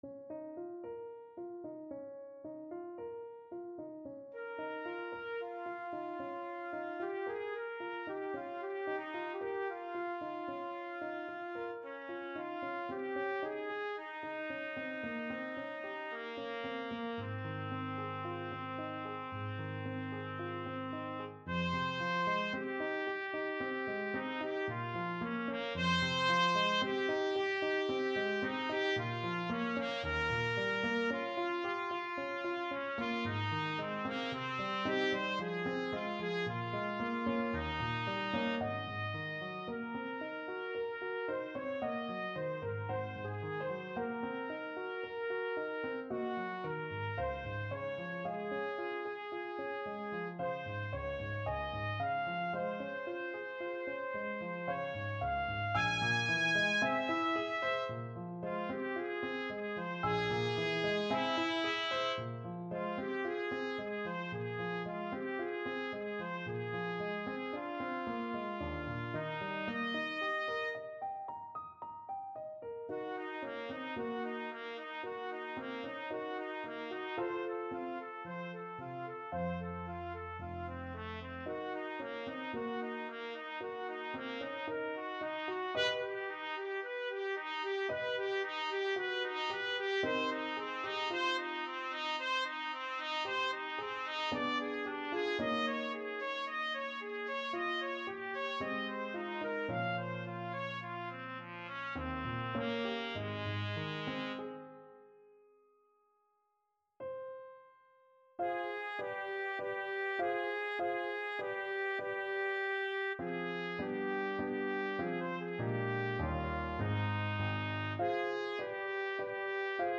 4/4 (View more 4/4 Music)
F4-Ab6
Classical (View more Classical Trumpet Music)